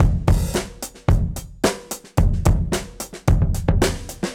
Index of /musicradar/dusty-funk-samples/Beats/110bpm
DF_BeatA_110-02.wav